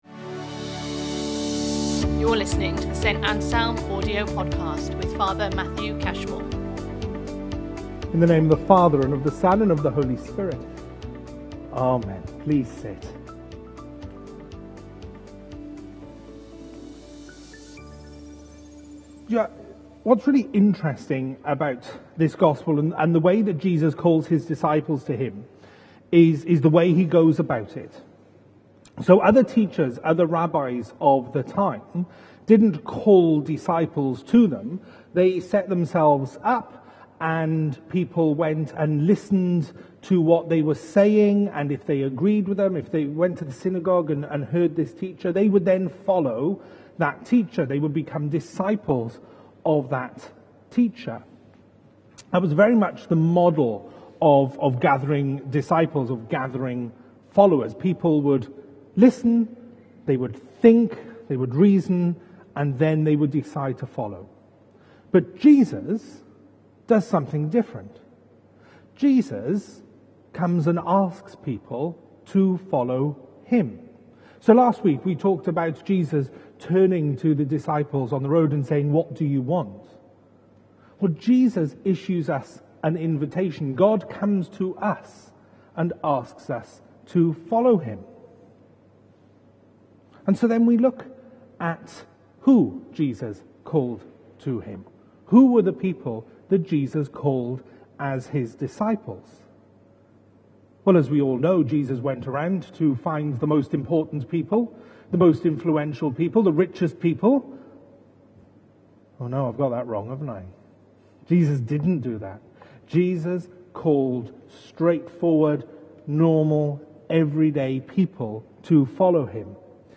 The Gospels Series Sunday Sermons Book Mark Watch Listen Read Save Mark 1:14-20 He calls us to service in His name and to bring other people into that service.